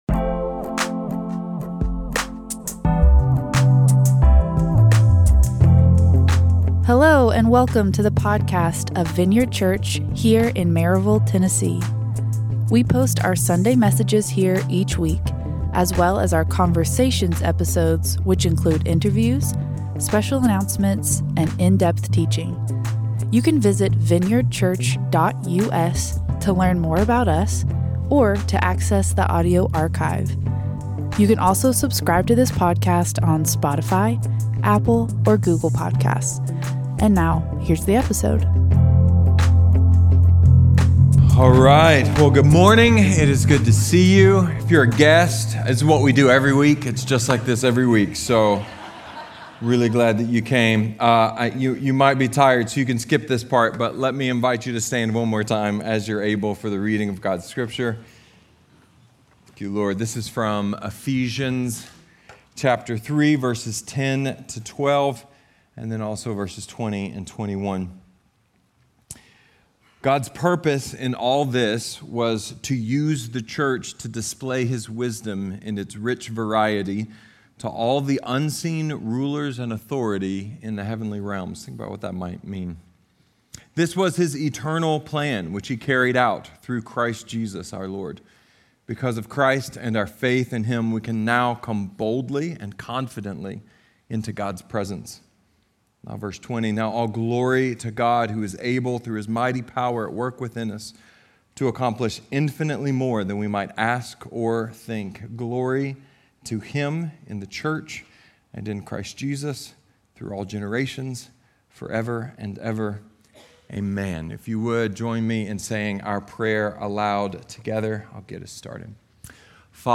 Happy Birthday, Vineyard! A sermon about how God is in control… but maybe we are too (at least a little)!